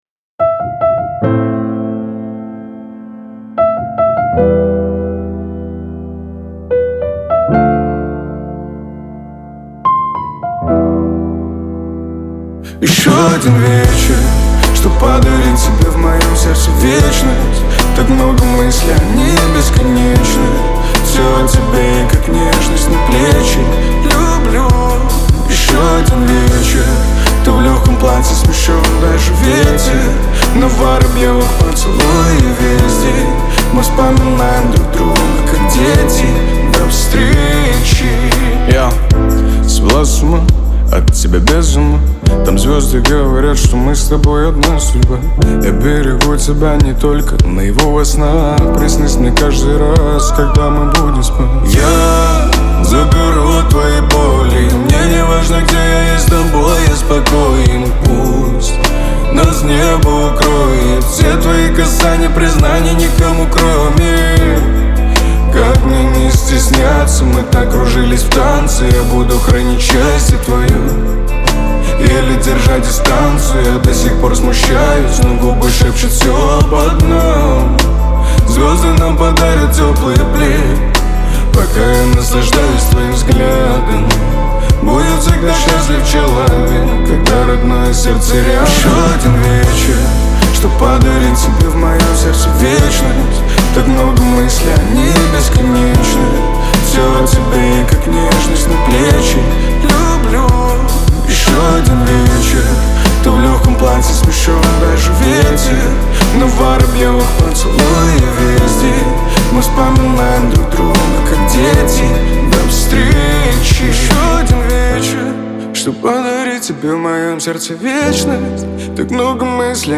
эстрада
pop